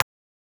Abstract Click (6).wav